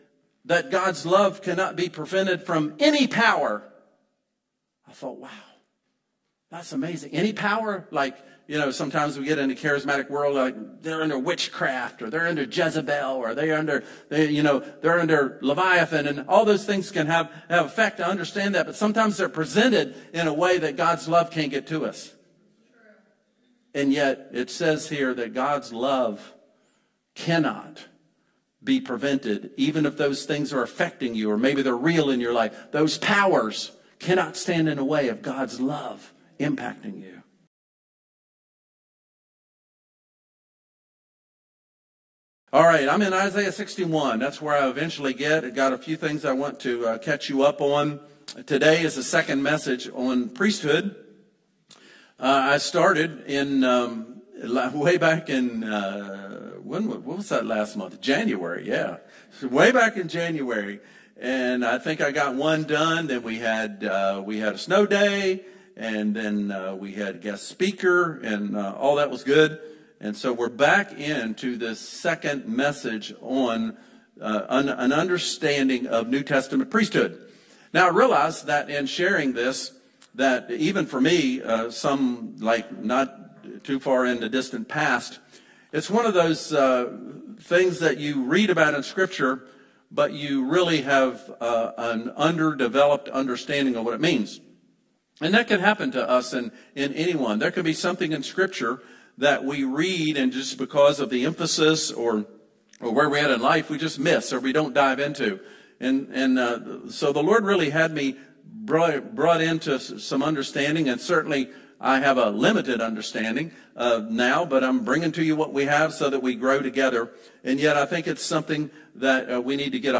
Sermon | Crossroads Community Church